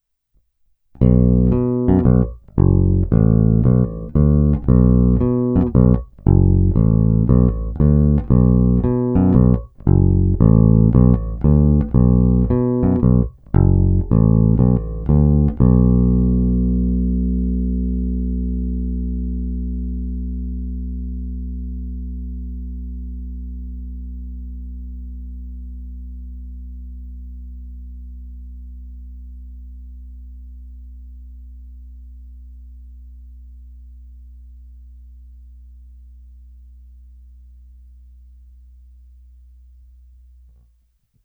Zvuk je plný, pevný, bohatý středobasový základ doplňuje slušná porce kousavosti.
Není-li uvedeno jinak, následující nahrávky jsou provedeny rovnou do zvukové karty, jen normalizovány, jinak ponechány bez úprav. Tónová clona vždy plně otevřená.
Hra mezi snímačem a kobylkou